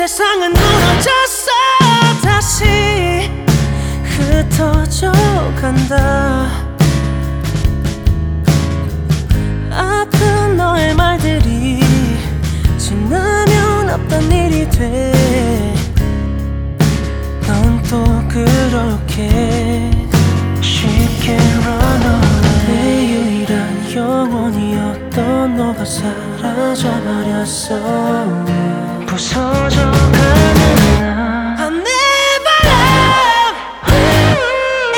Жанр: Поп музыка / Рок
K-Pop, Pop, Rock